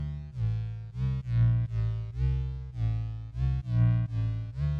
A (A Major - 11B) Free sound effects and audio clips
• bass background lowpass.wav
Bass_Background__lowpass__YhN.wav